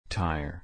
/ˈtaɪ̯ə(ɹ)/